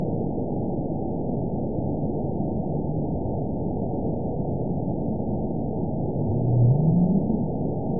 event 912864 date 04/03/22 time 19:08:05 GMT (3 years, 1 month ago) score 9.55 location TSS-AB02 detected by nrw target species NRW annotations +NRW Spectrogram: Frequency (kHz) vs. Time (s) audio not available .wav